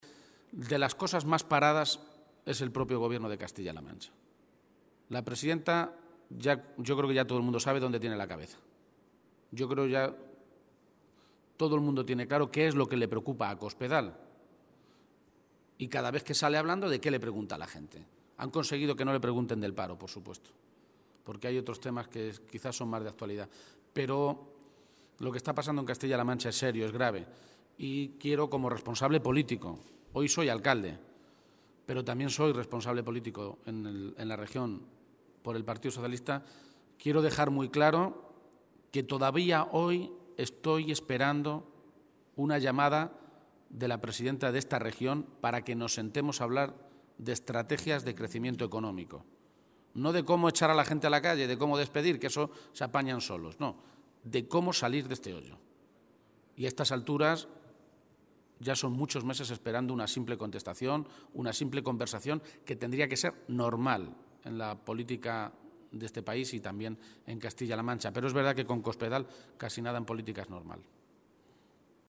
Emiliano García-Page, Secretario General del PSOE de Castilla-La Mancha
Cortes de audio de la rueda de prensa